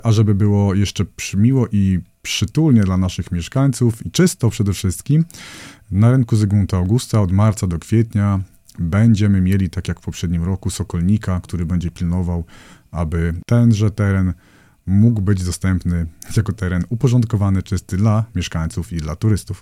– Sokolnik będzie pracował w marcu i kwietniu – poinformował Radio 5 Sławomir Sieczkowski, zastępca burmistrza Augustowa.